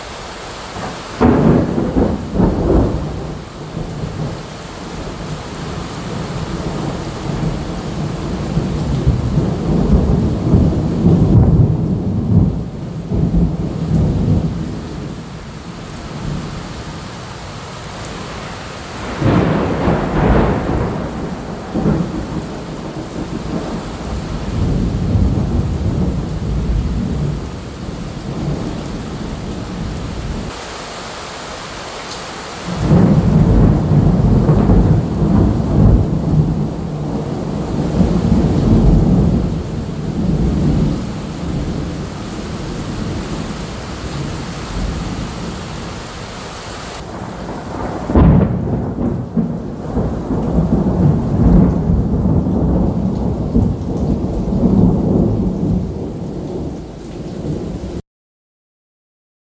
もうこっちは収まって雨だけになりましたが，結構な雷でした。